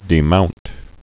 (dē-mount)